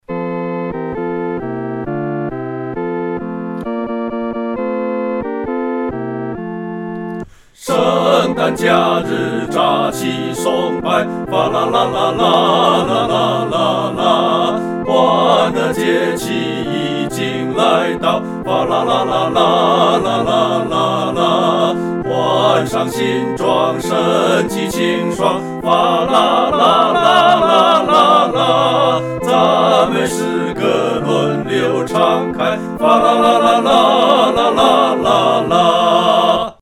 合唱（四声部）